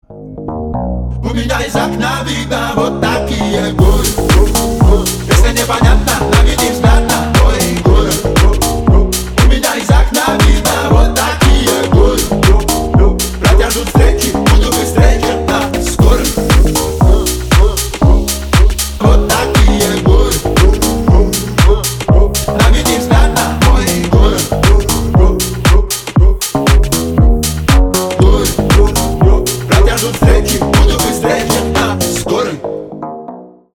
клубный рингтон 2025